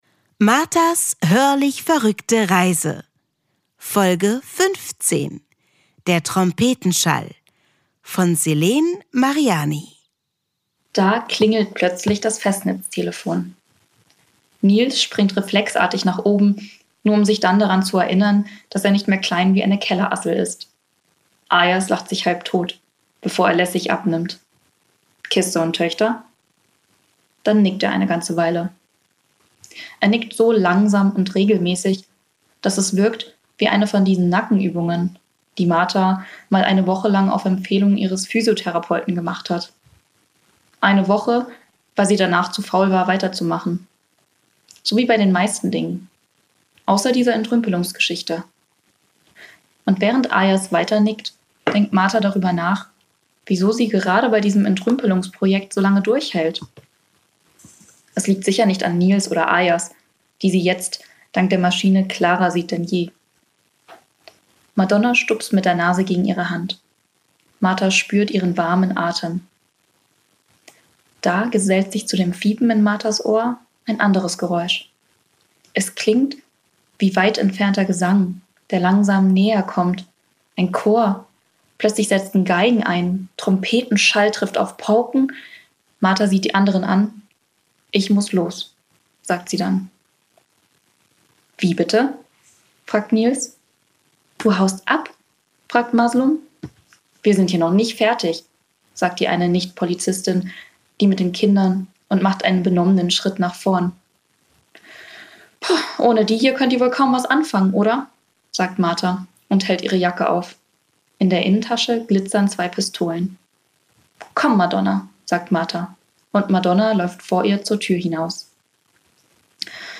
Eine Fortsetzungsgeschichte in 24 Kapiteln
Schauspielerin und Musikerin Denise M’Baye umrahmt die Kapitel als Gastgeberin.